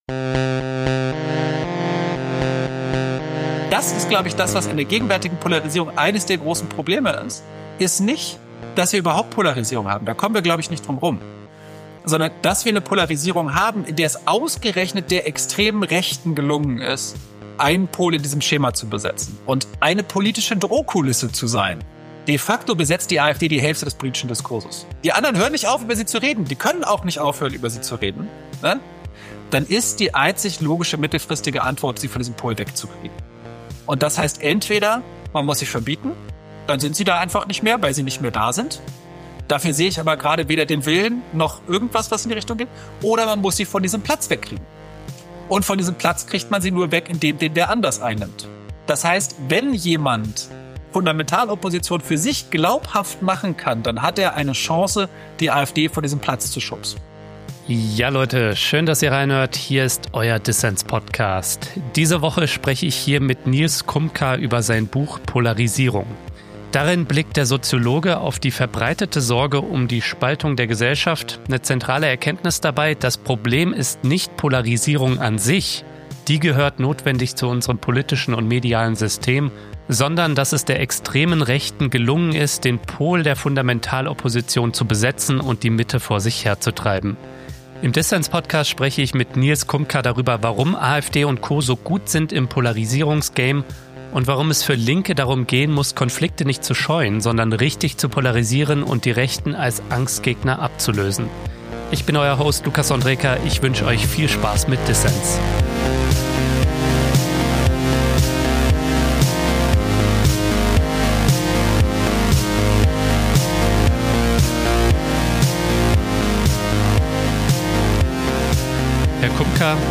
Ein Gespräch über den Erfolg der AfD im Polarisierungs-Game, die Hilflosigkeit der politischen Mitte und Lehren für Linke.